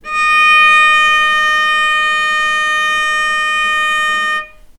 healing-soundscapes/Sound Banks/HSS_OP_Pack/Strings/cello/ord/vc-D#5-mf.AIF at 48f255e0b41e8171d9280be2389d1ef0a439d660
vc-D#5-mf.AIF